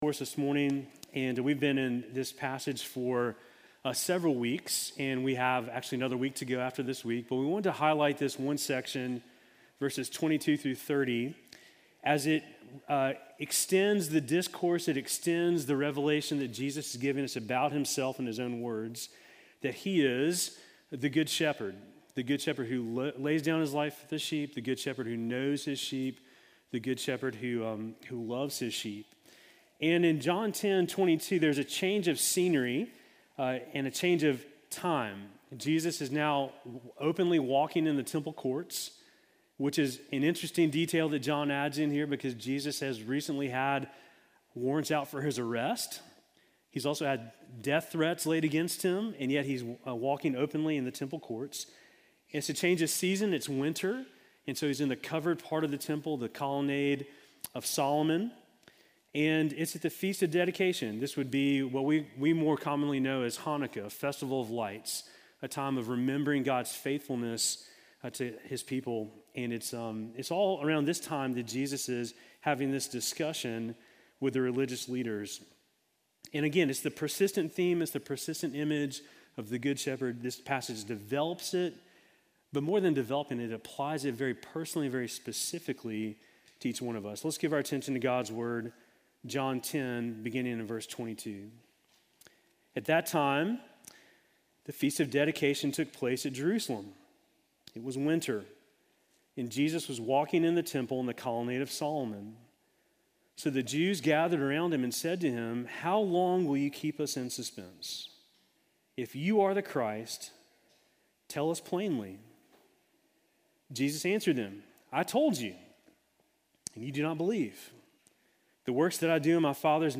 Sermon from October 26